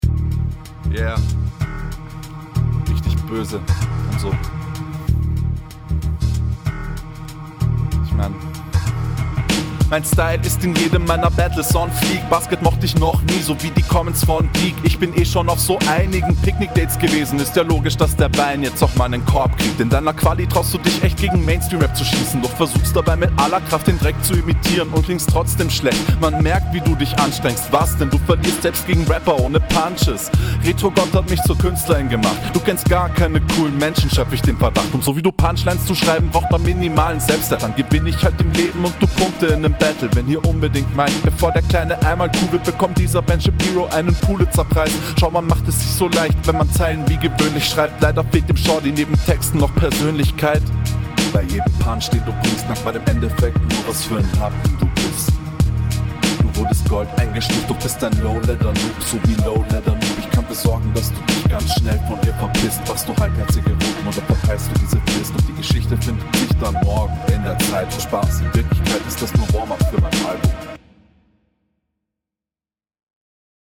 Vibe ist sehr cool, mische passt, ich würde die stimme n bissl leiser machen, damit …